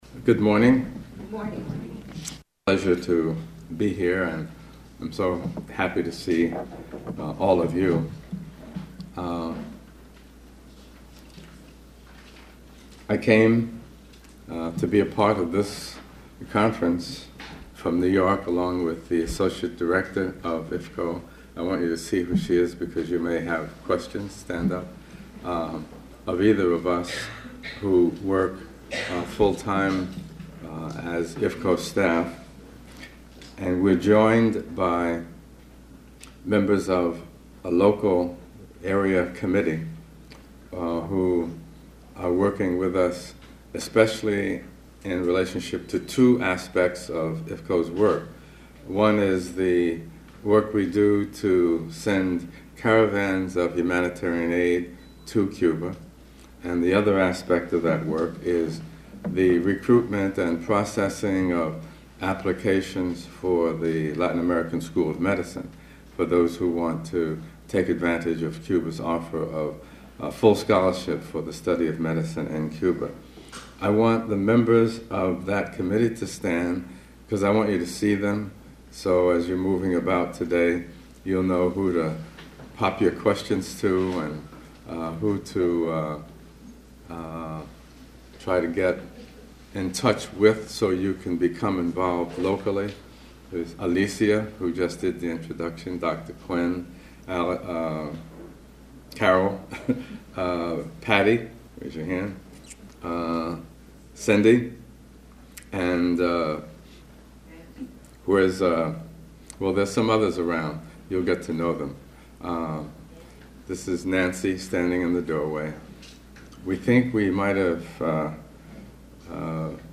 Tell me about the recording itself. Conference at Laney College promoting scholarships at the Latin American School of Medicine